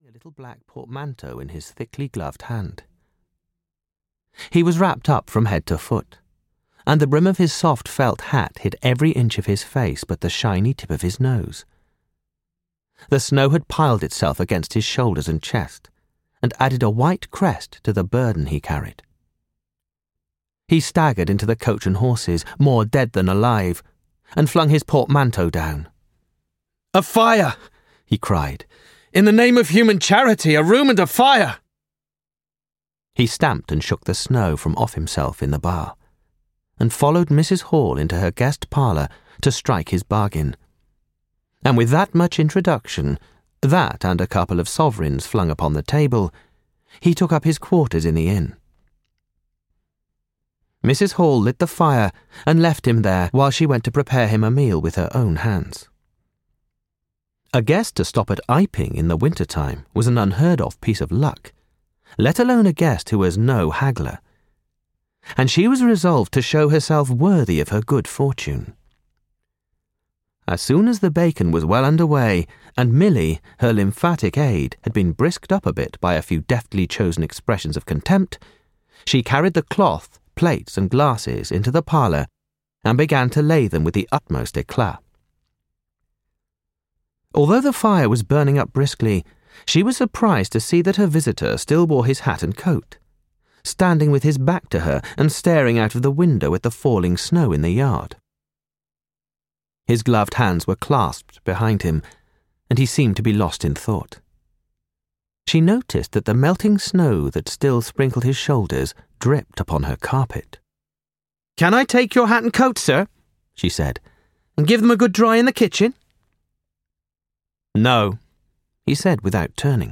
The Invisible Man (EN) audiokniha
Ukázka z knihy